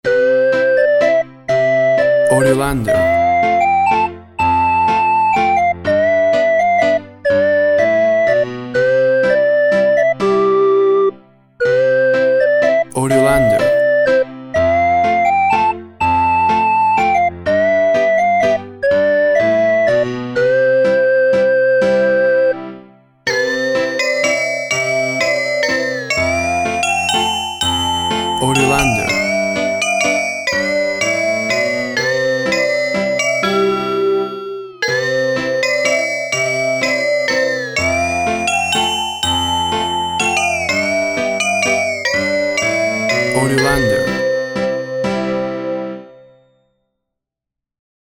Tempo (BPM) 124